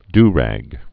(drăg)